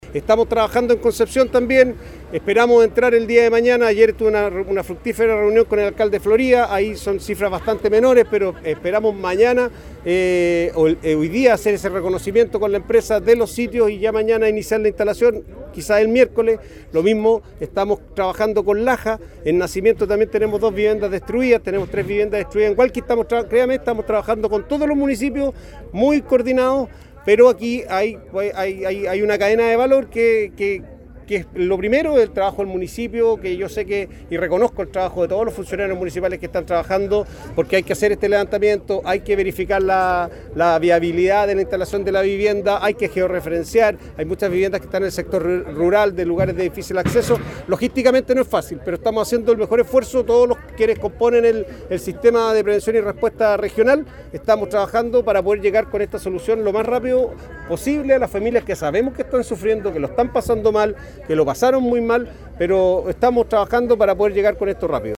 Por su parte, el director regional de Senapred, Alejandro Sandoval, valoró el trabajo de los equipos municipales, considerando que “logísticamente, no es fácil” desarrollar todo este proceso, que implica acceder a los sitios afectados a levantar información, entregar los datos apropiados a los distintos servicios y coordinar los trabajos con empresas privadas y voluntarios.